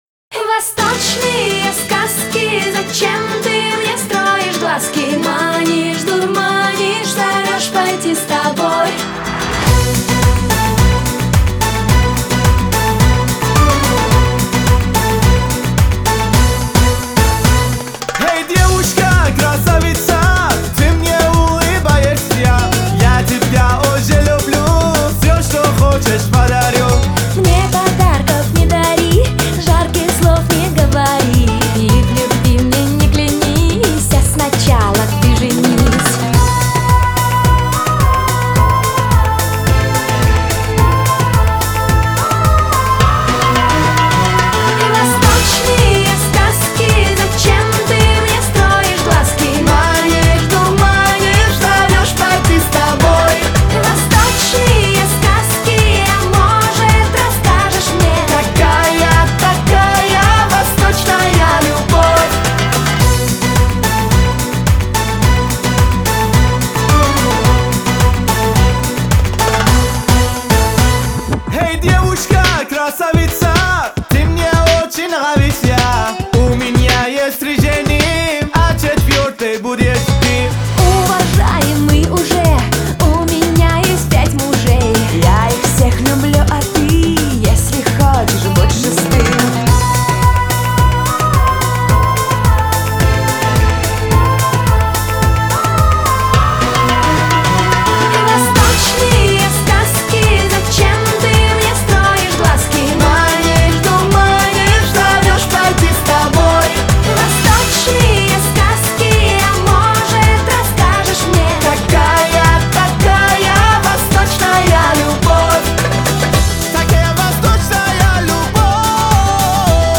آهنگ پاپ